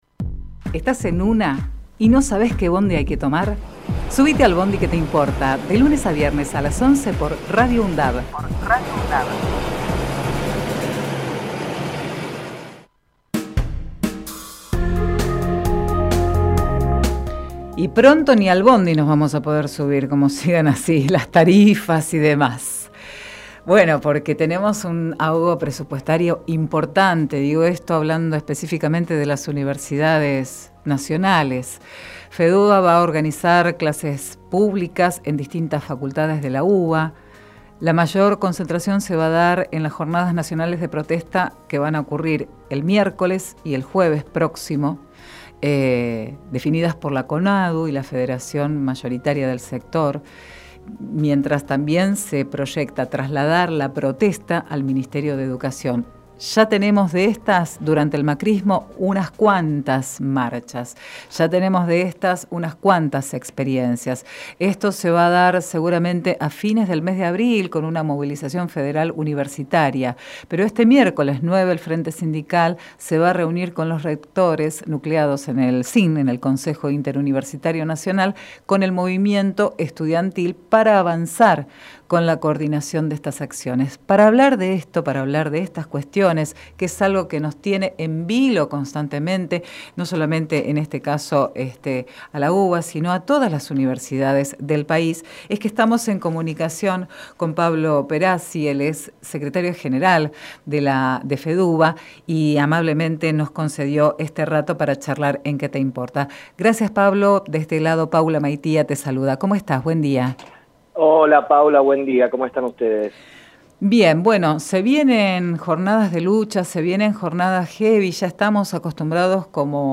Compartimos entrevista realizada en "Que te Importa"